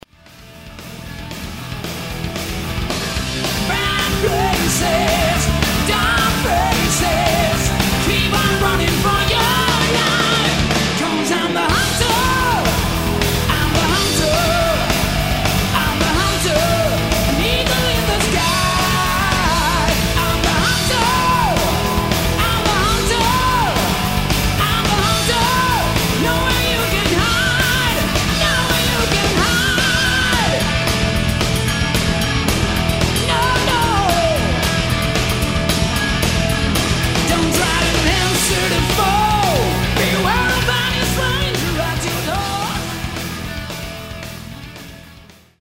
Swiss Hard Rock band
For pure hard driven rock and roll